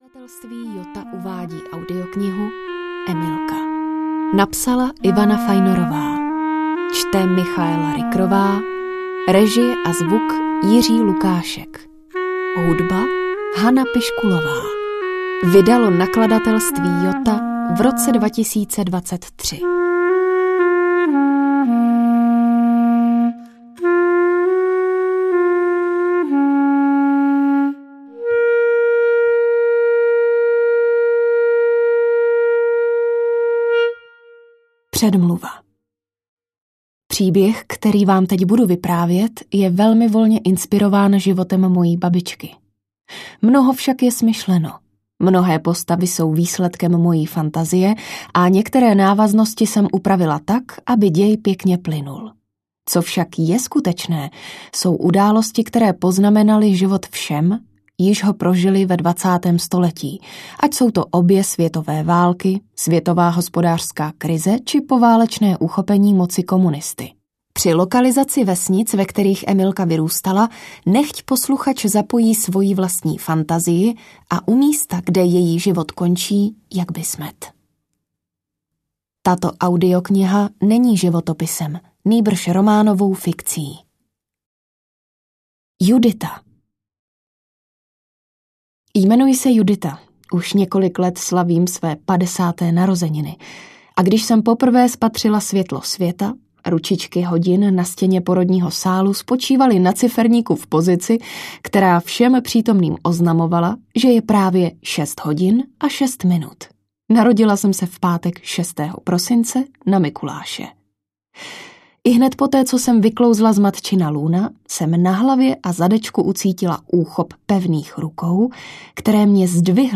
Emilka audiokniha
Ukázka z knihy